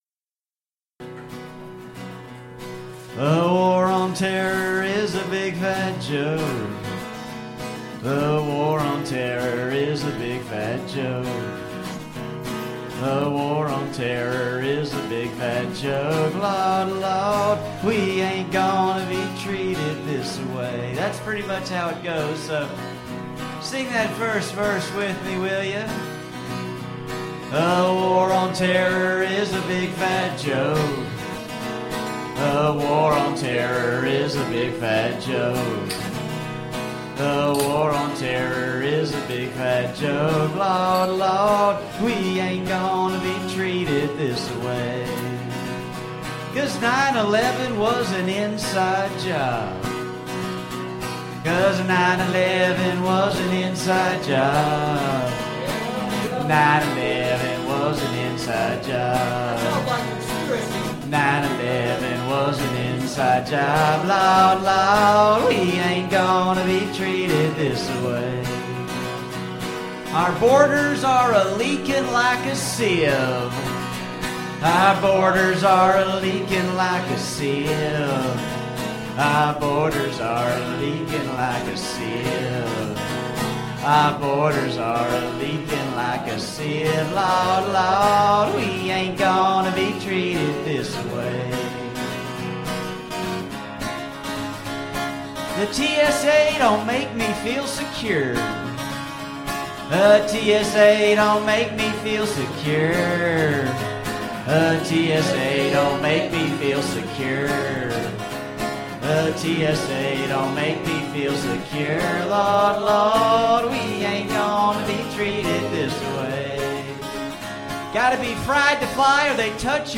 Tune guitar down ½ step!